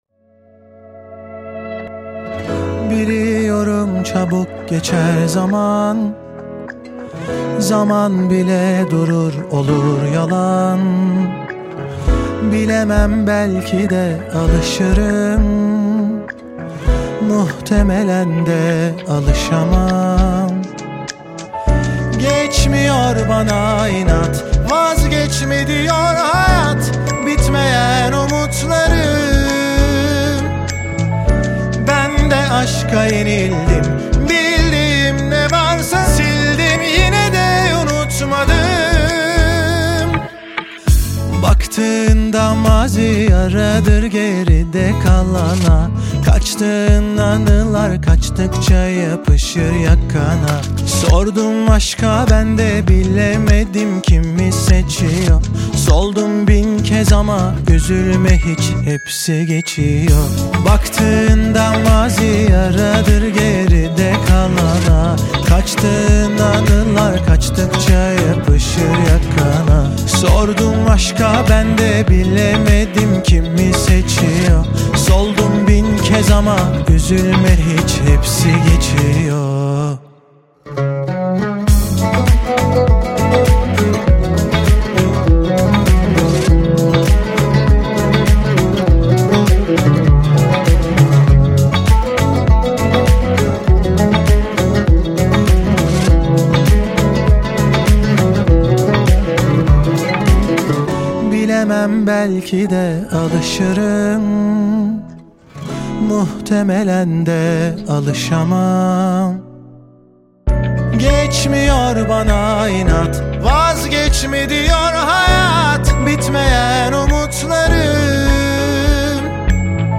ژانر: پاپ ، رپ